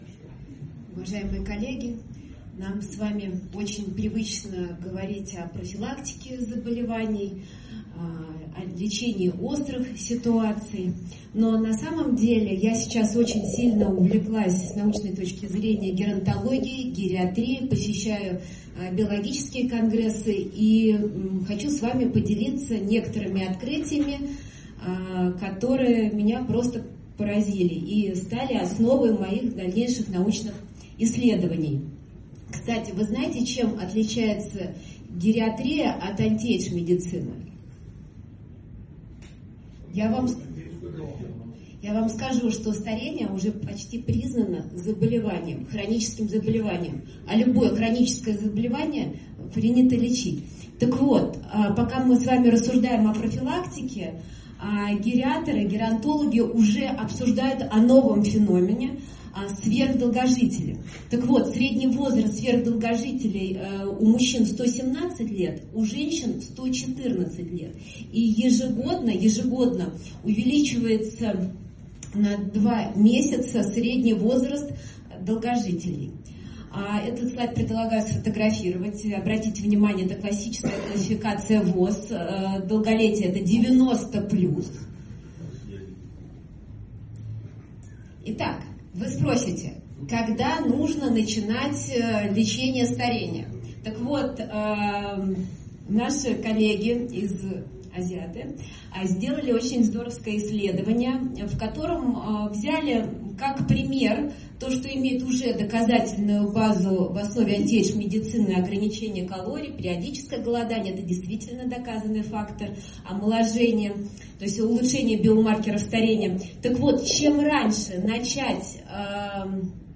Источник: XXI Конгресс "Мужское здоровье" в г.Сочи